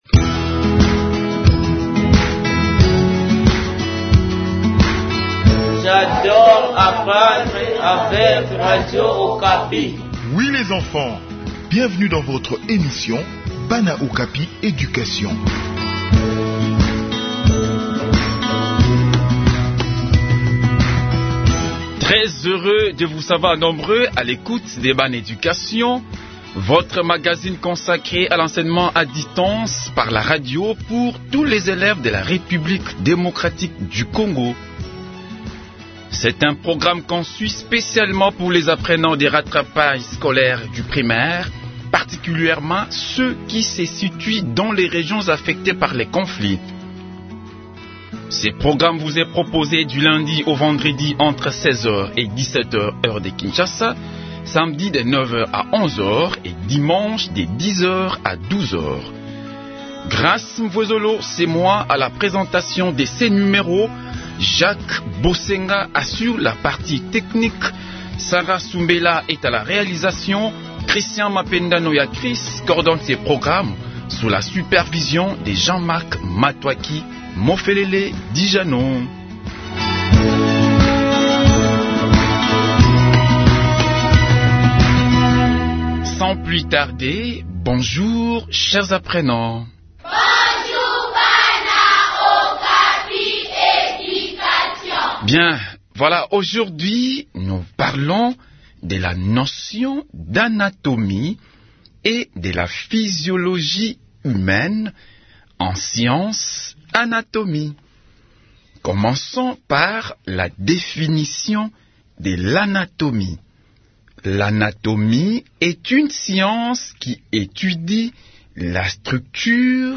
Enseignement à distance : leçon sur l’anatomie et la physiologie humaine